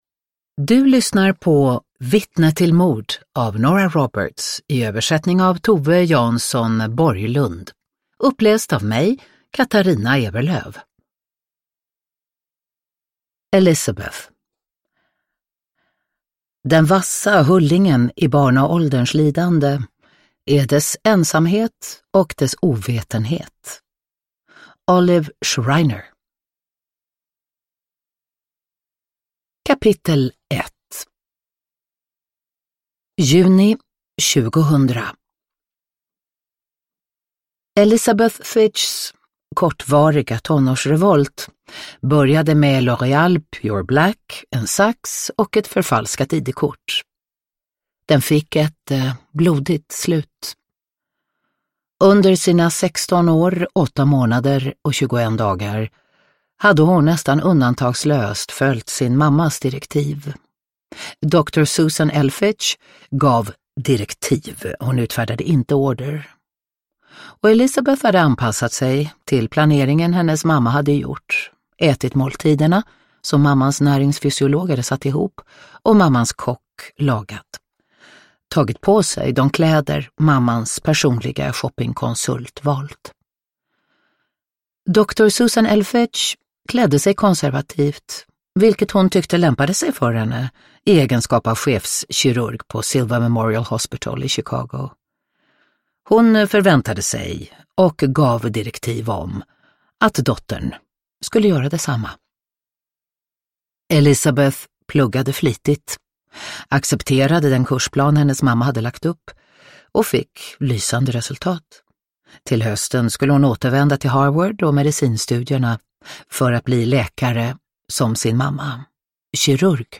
Vittne till mord – Ljudbok
Uppläsare: Katarina Ewerlöf